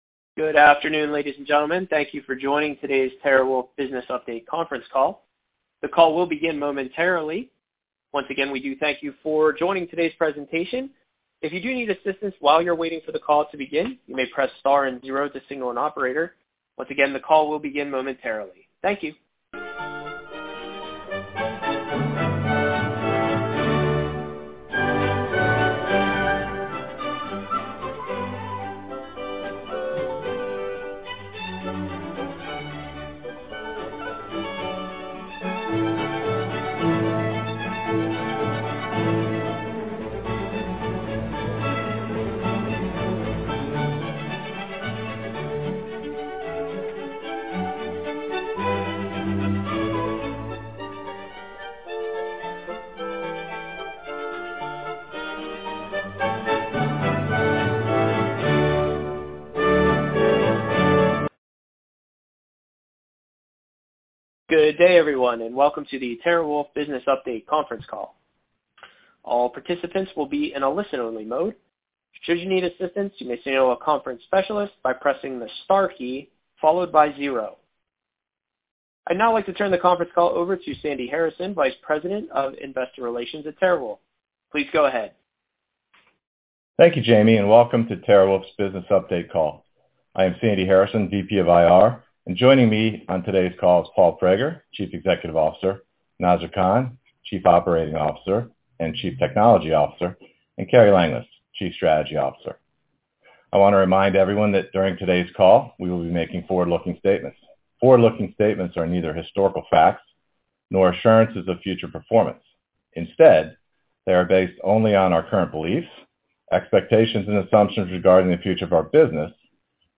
TeraWulf Business Update Conference Call